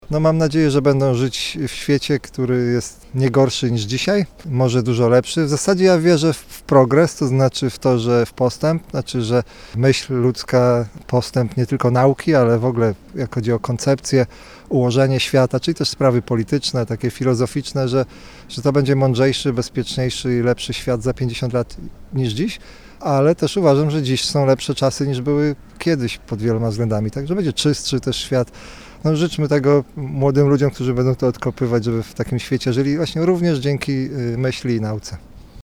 Jakich zatem życzeń na przyszłość, która czeka kolejne pokolenia studentów, udzielił rektor — prof. Arkadiusz Wójs.